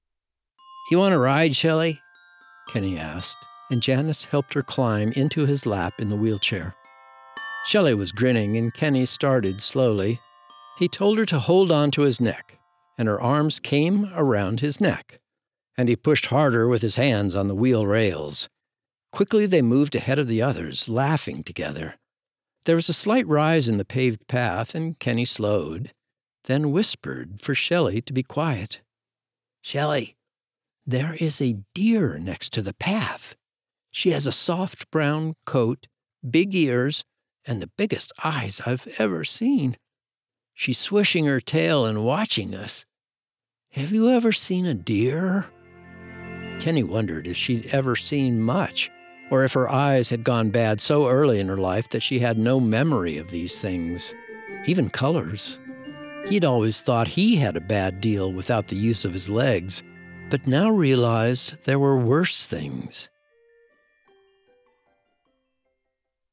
Christmas Sugar Cookies audiobook
Short Story – Rated G – 15 minutes